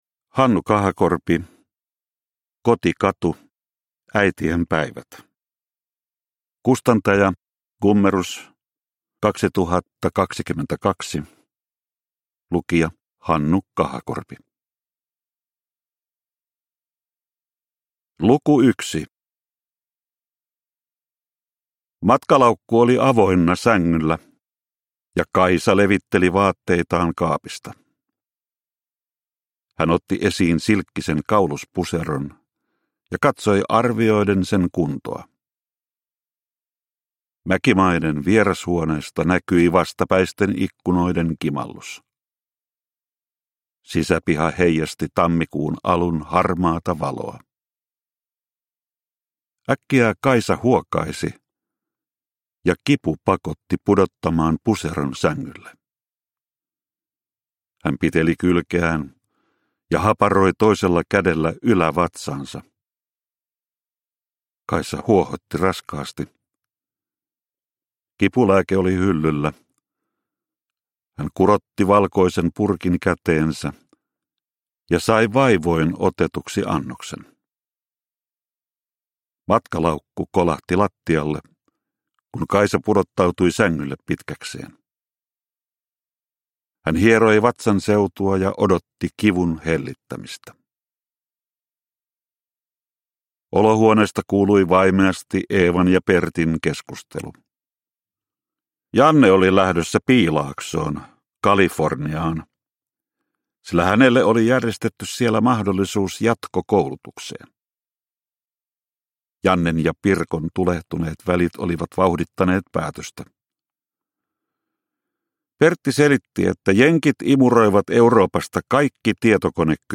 Kotikatu - Äitien päivät – Ljudbok – Laddas ner